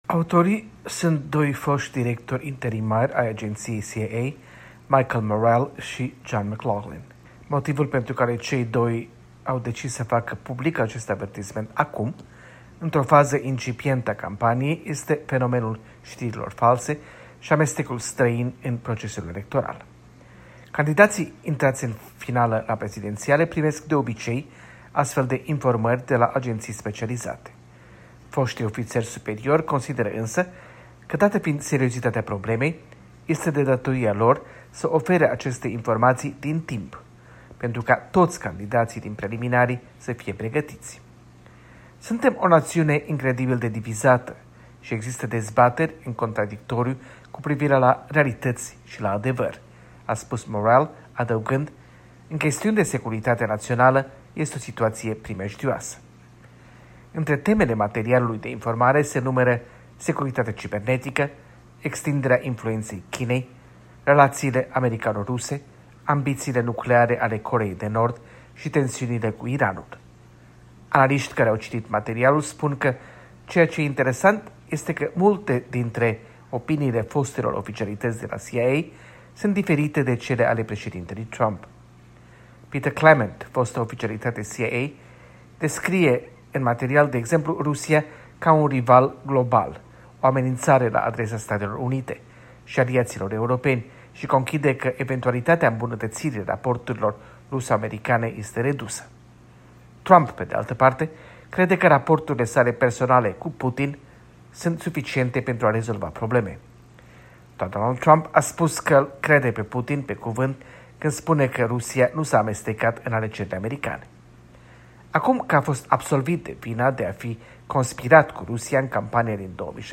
Corespondența zilei de la Washington